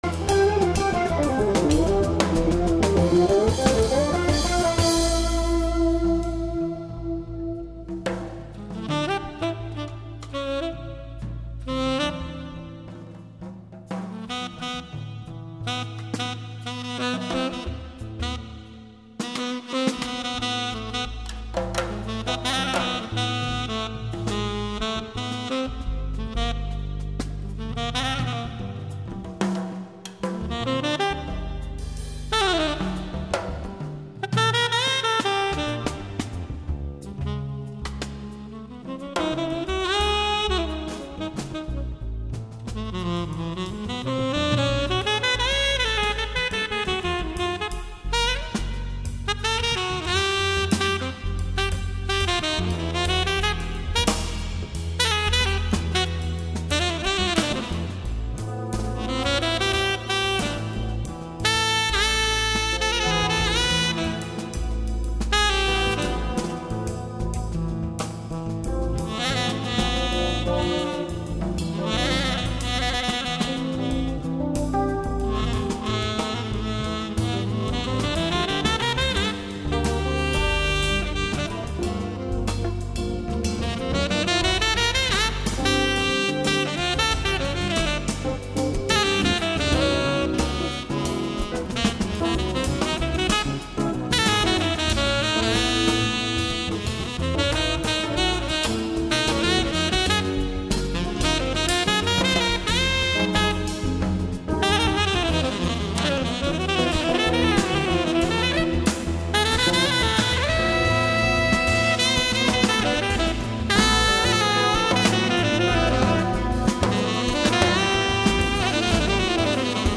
(Impro Tenor sax)